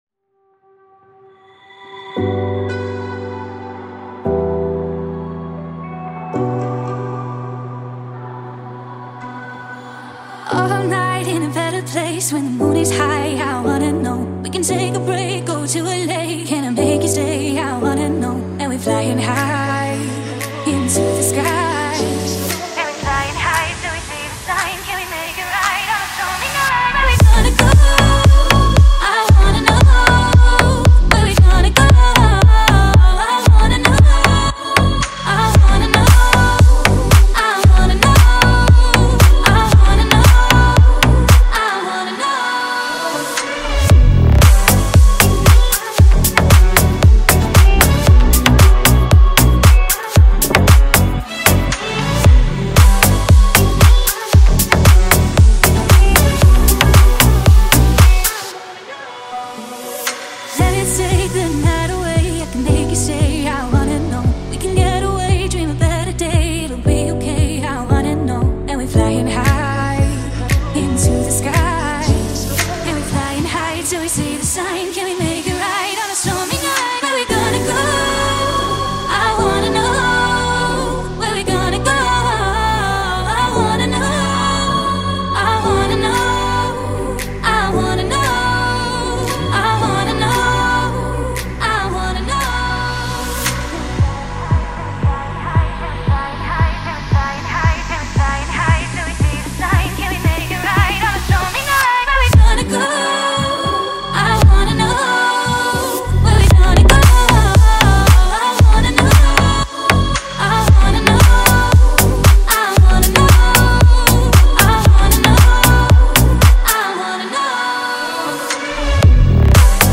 این اهنگ یک حس رویایی میده.